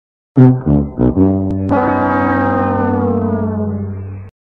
Fail Sound Effect Free Download